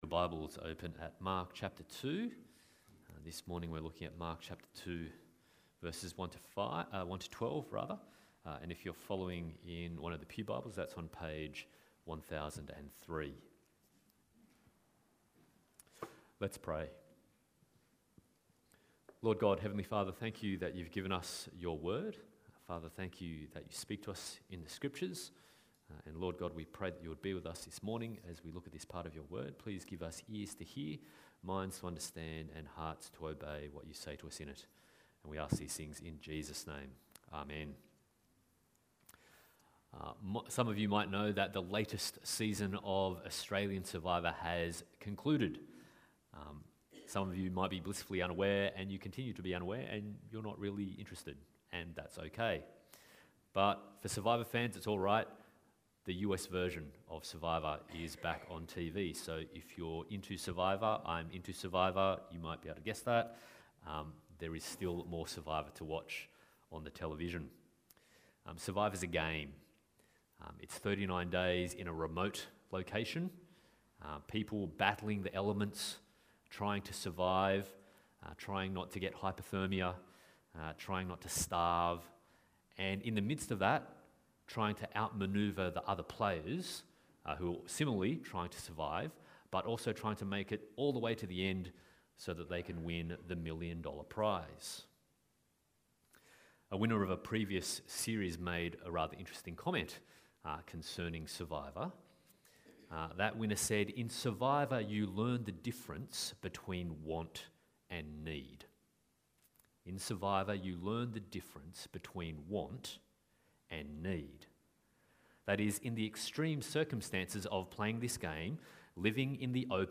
Psalm 32:1-11 Service Type: Sunday Morning « Paul Escapes Paul before Felix